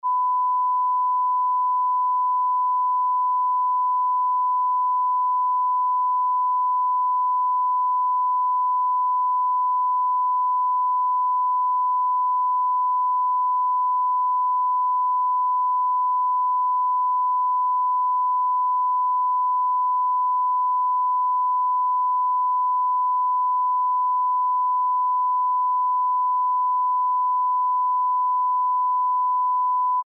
Yus, the MP3 also comes out bang on -18.
neg 18 1khz01.mp3